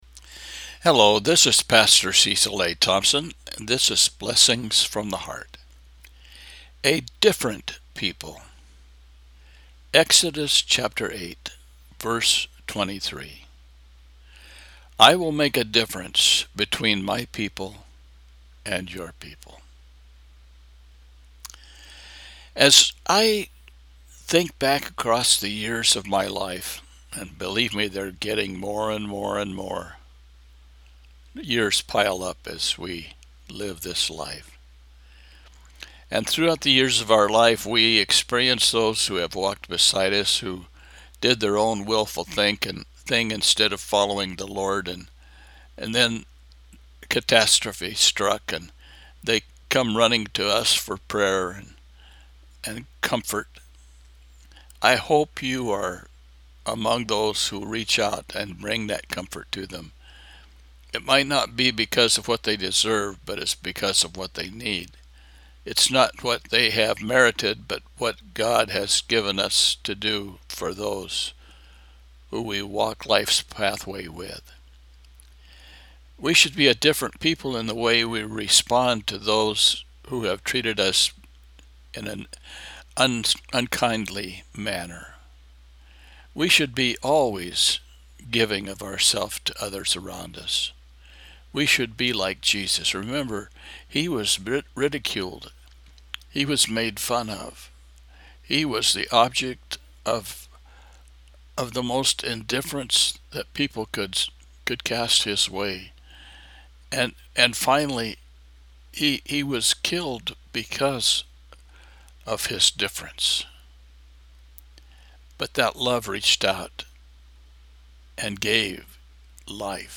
Exodus 36:6-7 – Devotional